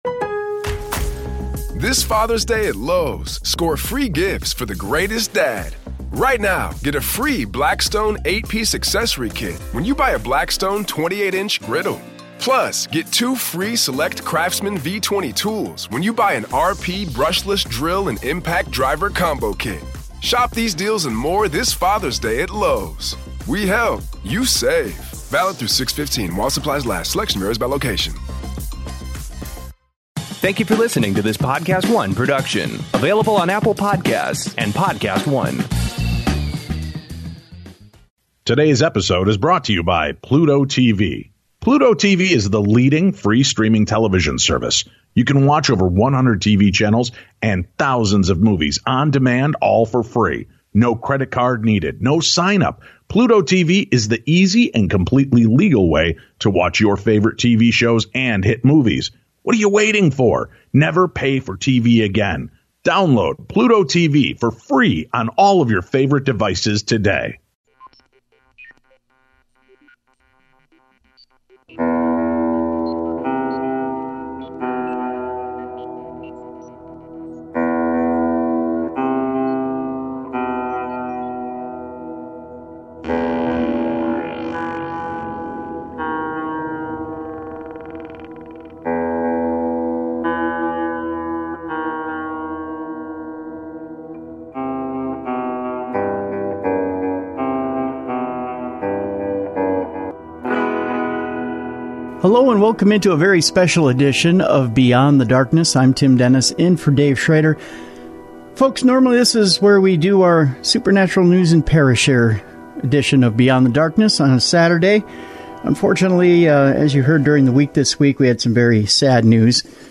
we celebrate Stanton's life by replaying an interview with Stanton and his fellow Co-Author